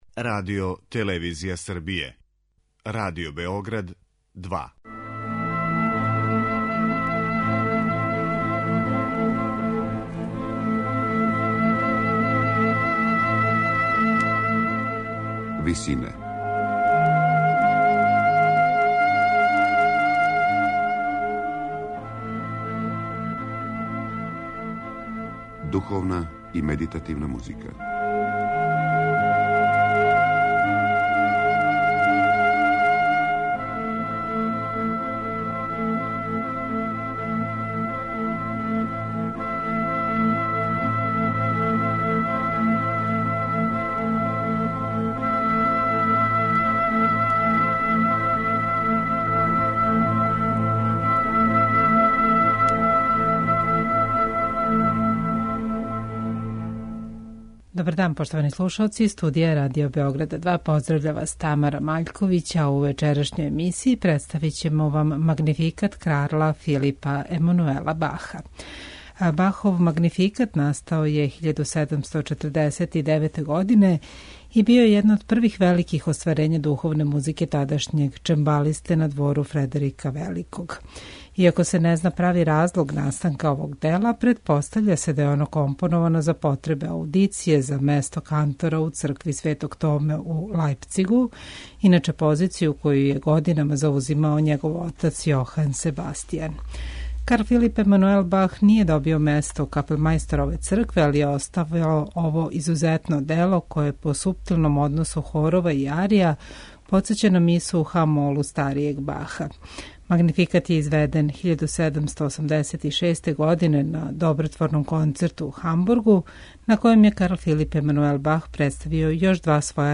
Ово је једно од његових првих великих вокално-инструменталних дела, настало највероватније поводом аудиције за место кантора у Цркви Светог Томе у Лајпцигу.